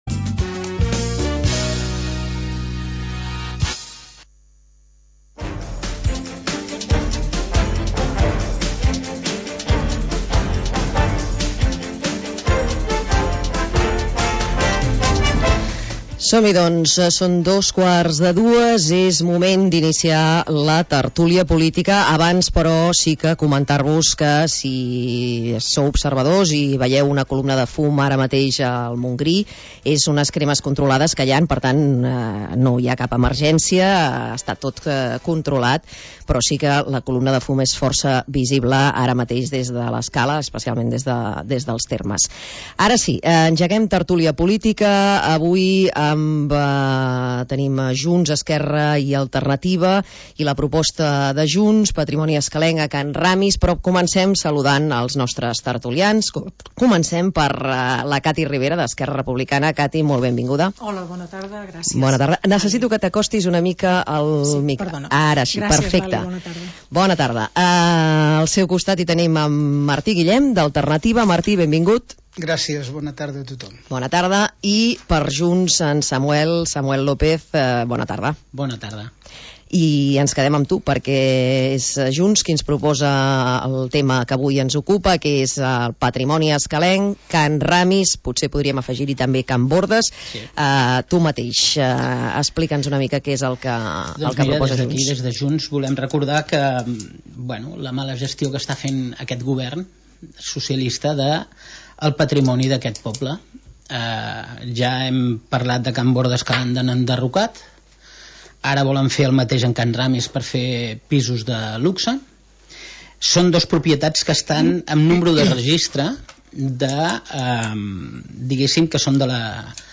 Tertúlia de caire polític